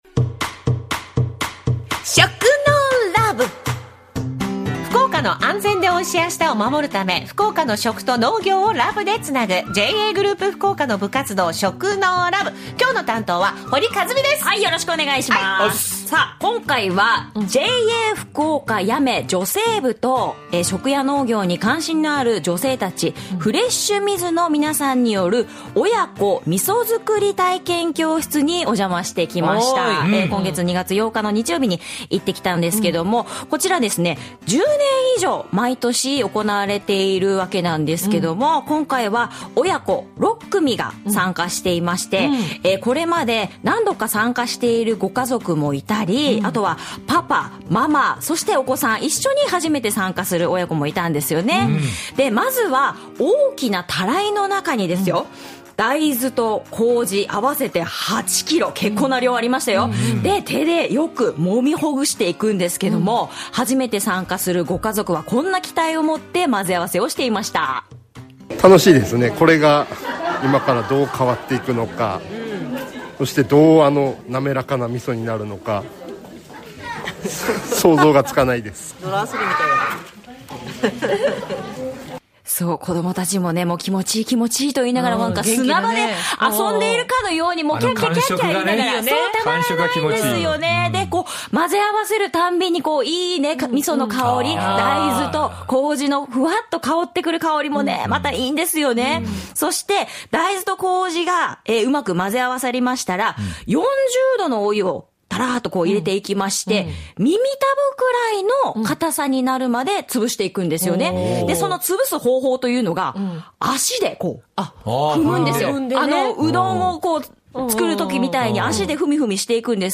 子どもたちも気持ちいい～と言いながら砂場で遊んでいるかのように混ぜていました♪ そして、大豆と麹が混ぜ合わさったら40度のお湯を入れて、耳たぶくらいの硬さになるまで 潰していくのですが足で踏んで固めていきます。 足にビニール袋をかけて踏み踏み・・・ 女性部の皆さんが威勢のいい掛け声や歌をうたいながら、ふみふみする親子に声援を送り、賑やかに楽しくみそ作りをしていました。